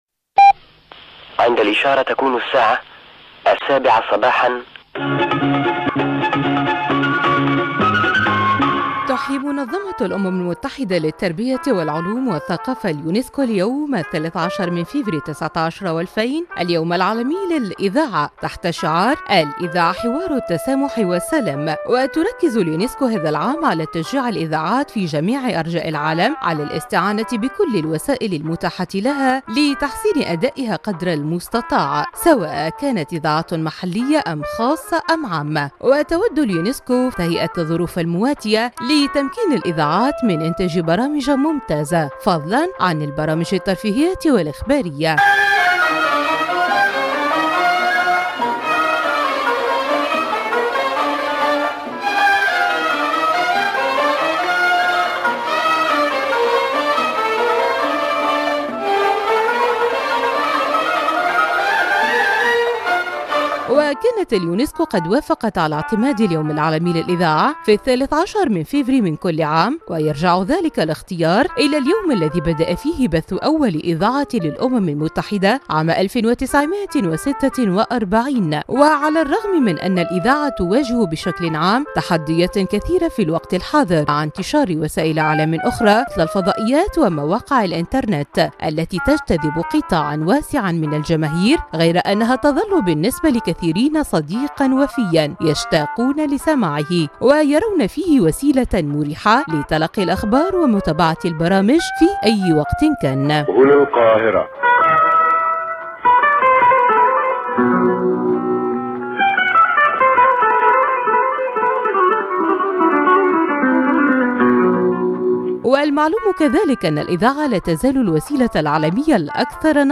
تقرير